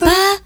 Note 6-G.wav